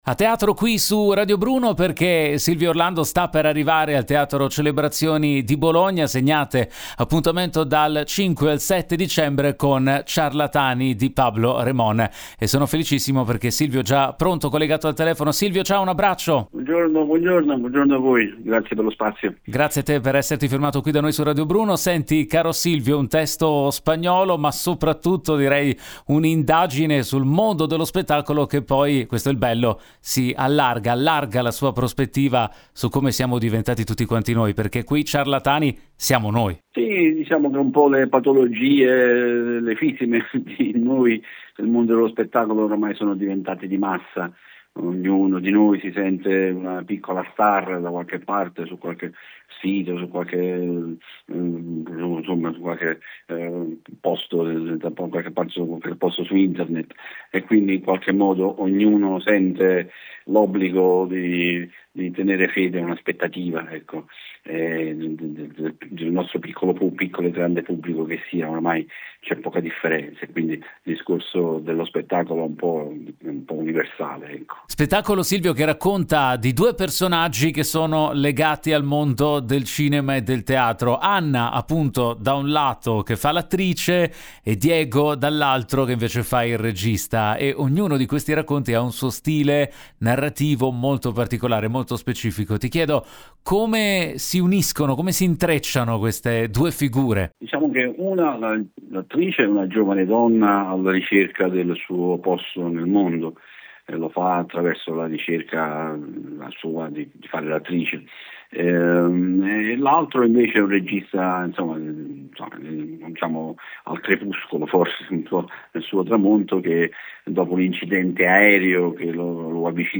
Silvio Orlando, intervistato